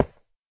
sounds / dig / stone2.ogg
stone2.ogg